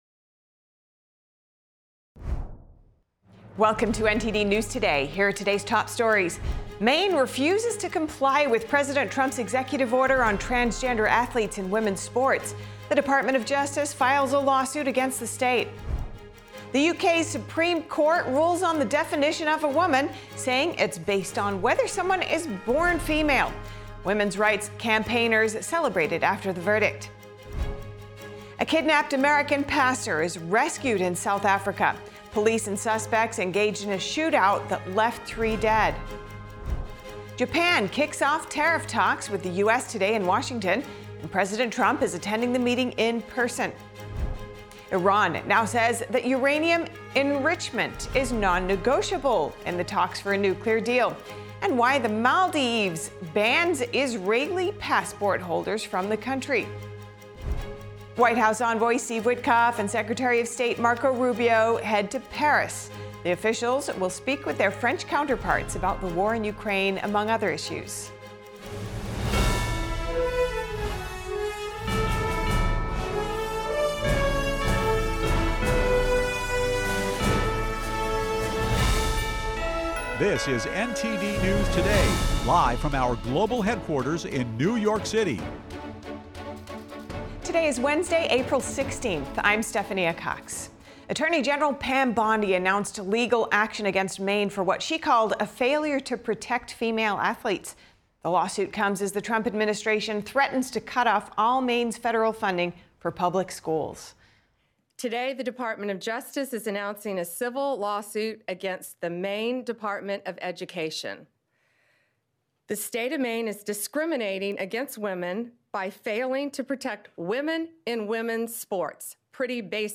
NTD-News-Today-Full-Broadcast-April-16-audio-converted.mp3